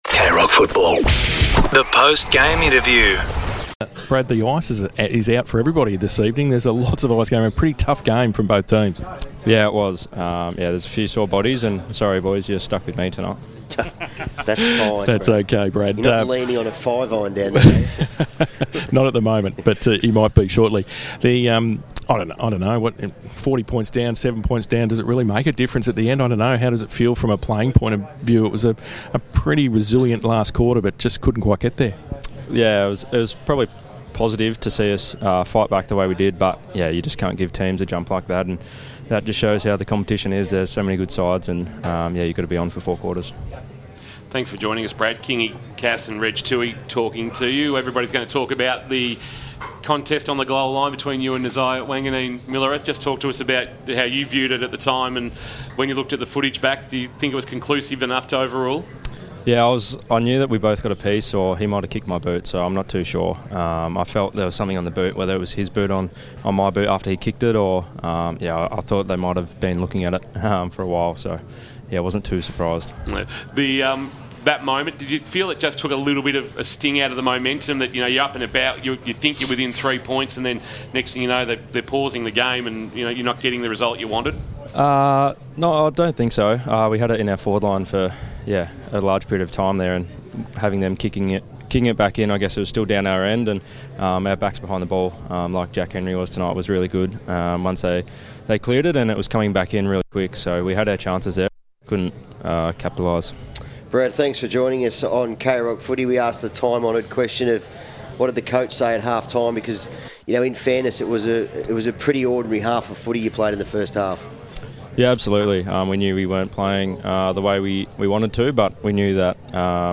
2025 - AFL Round 2 - St Kilda vs. Geelong: Post-match interview - Brad Close (Geelong)